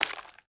Sfx_creature_trivalve_scuttle_slow_front_legs_08.ogg